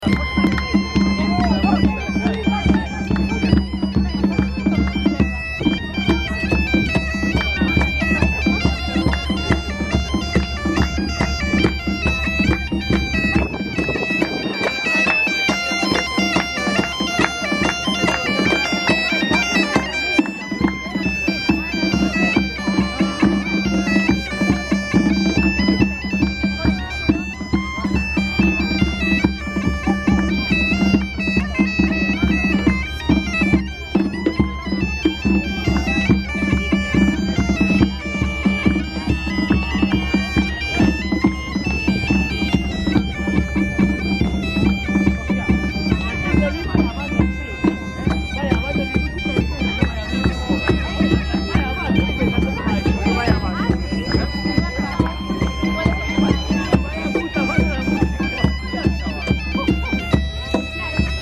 Her marine procession is accompanied by the sound of bagpipes, horns, and percussion, which upon her return to the mainland are drowned out by a barrage of rockets launched to the sky celebrating her maritime journey.
gaitas.mp3